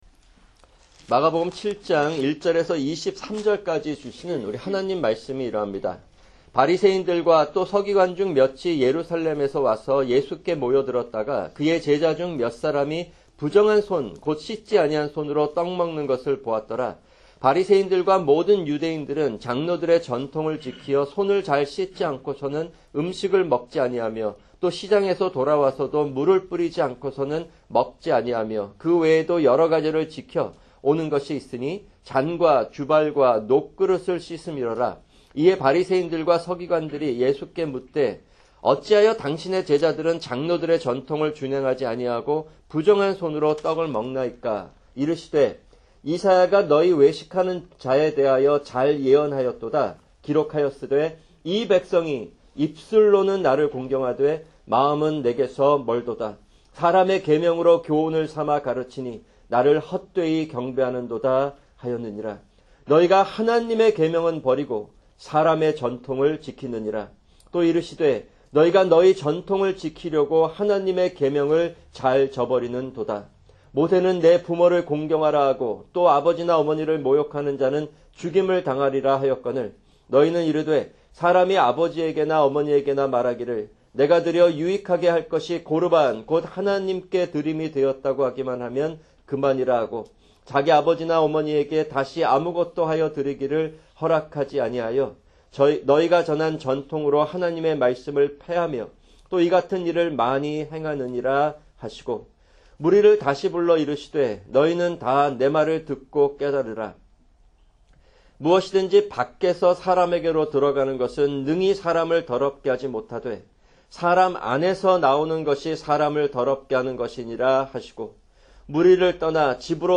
[주일 설교] 마가복음(32) 7:1-23(2)